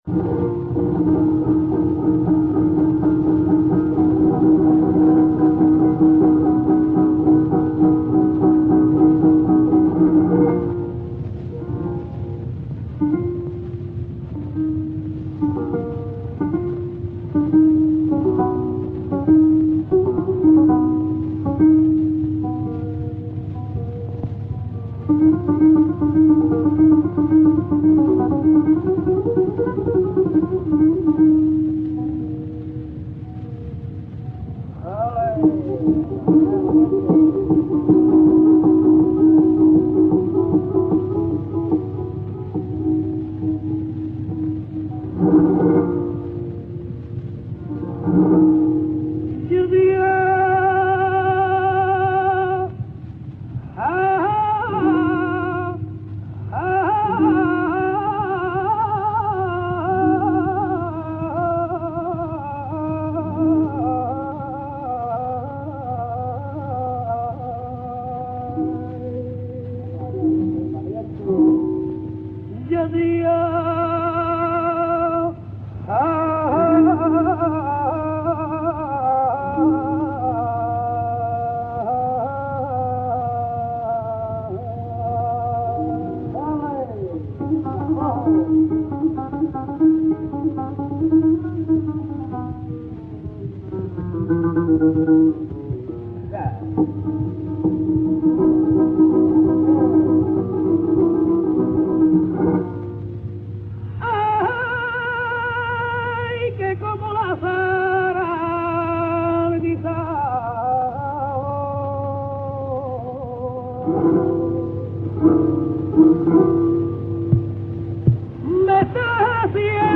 Surtout, son style vocal singulier rend ses interprétations fondamentalement originales, quel que soit le modèle de référence : sur le plan mélodique, un usage intensif des notes de passage chromatiques, souvent accompagnées de portamentos ; sur le plan ornemental, un continuum vibrato élargi/mélismes, le passage de l’un aux autres étant souvent indiscernable — de plus, contrairement à l’usage, ses mélismes plongent fréquemment sous la note porteuse, et procèdent parfois par notes disjointes, tempérées ou non.
taranto_1_taranta_del_cojo_1921.mp3